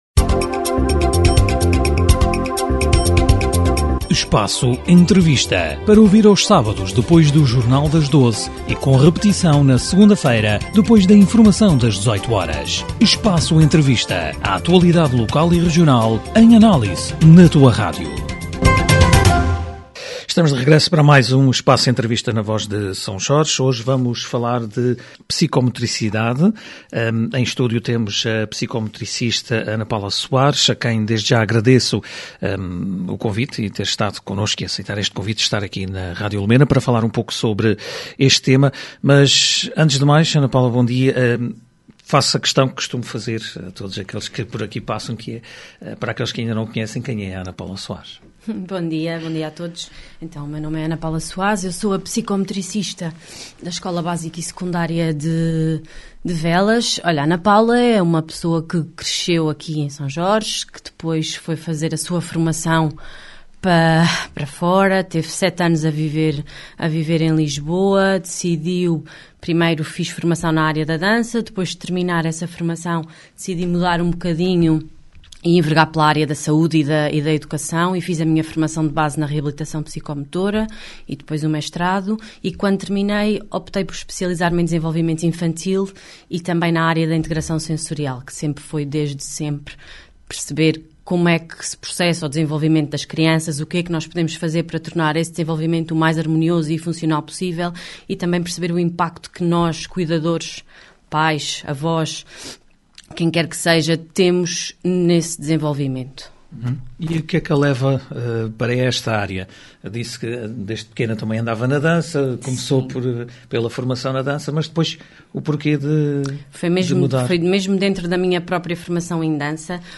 Espaço Entrevista